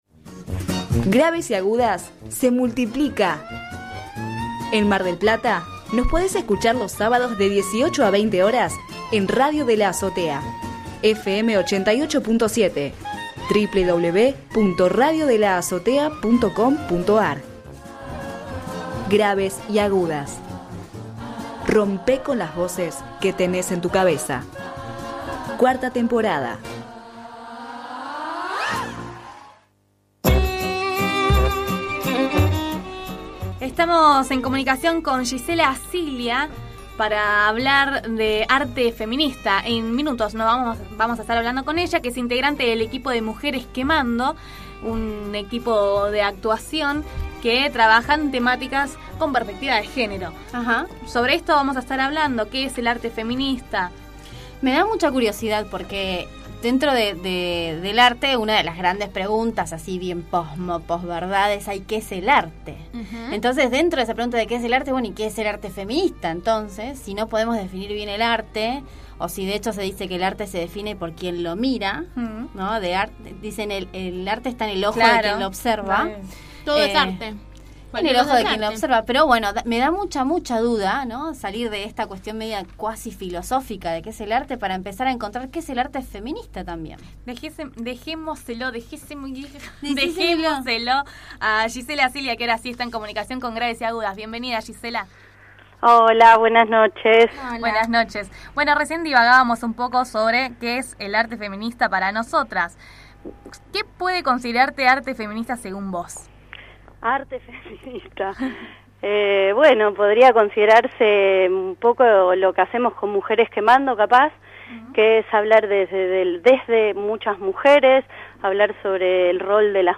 Graves y agudas / Entrevista